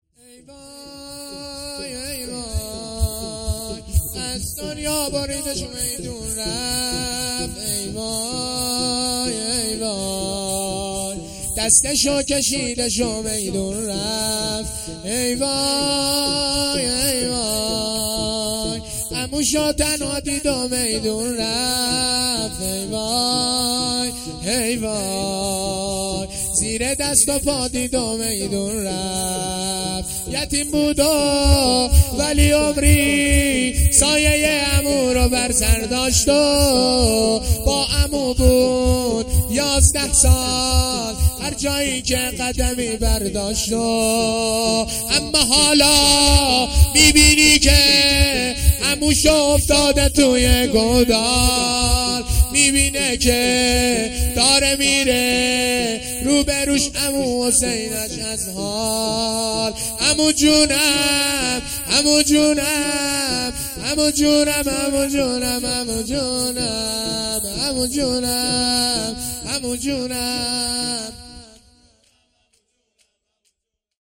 شور
شب پنجم محرم الحرام ۱۴۴۳